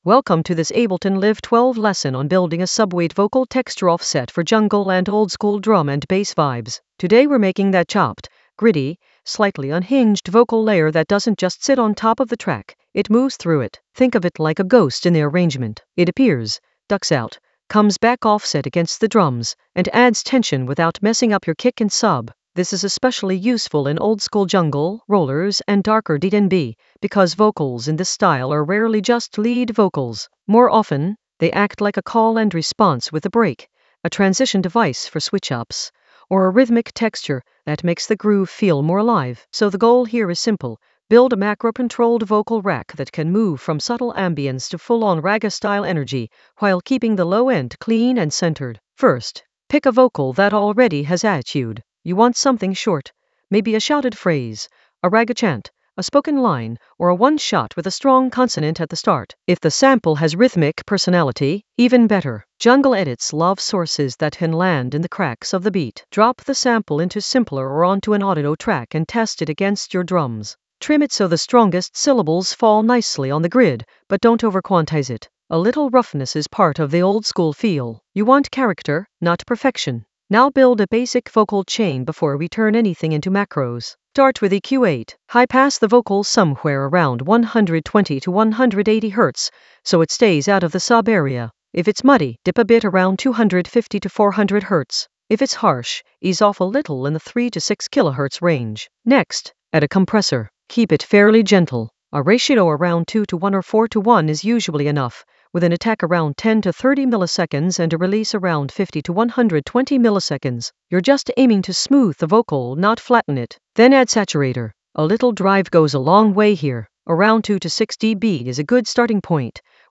An AI-generated intermediate Ableton lesson focused on Subweight vocal texture offset playbook using macro controls creatively in Ableton Live 12 for jungle oldskool DnB vibes in the Edits area of drum and bass production.
Narrated lesson audio
The voice track includes the tutorial plus extra teacher commentary.